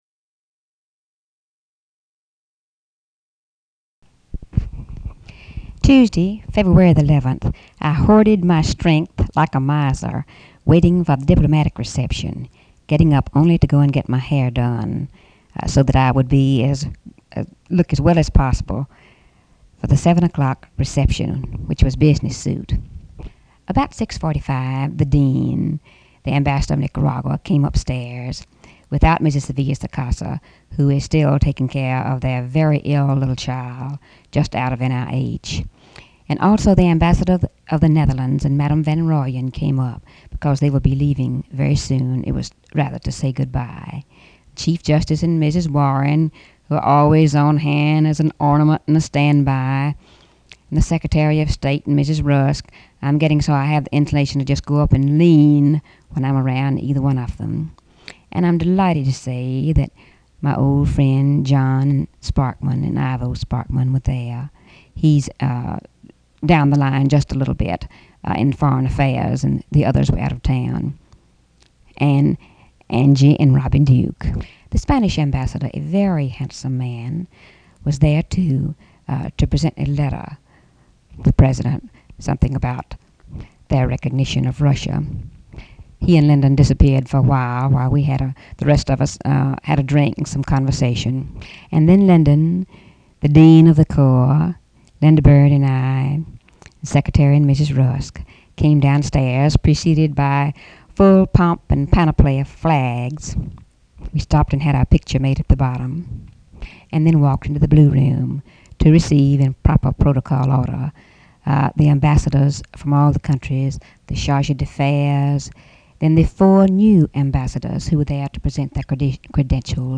Audio diary and annotated transcript, Lady Bird Johnson, 2/11/1964 (Tuesday) | Discover LBJ
White House, Washington, DC
Personal diary